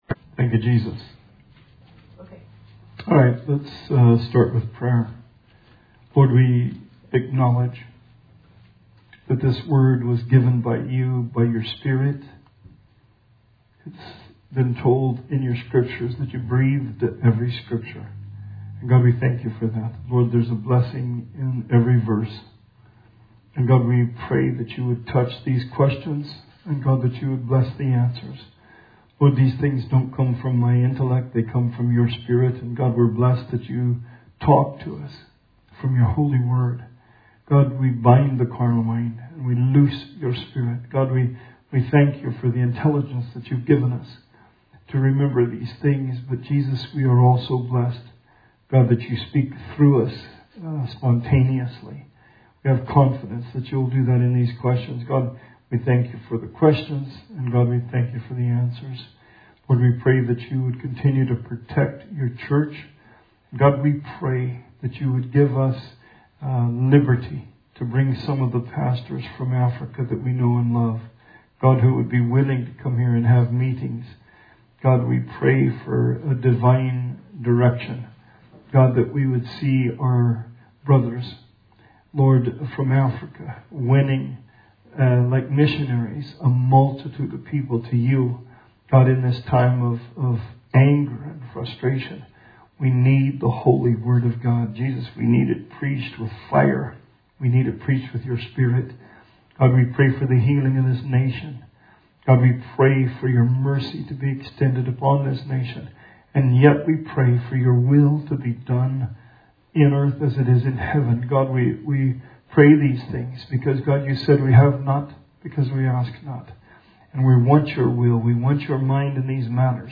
Bible Study 7/15/20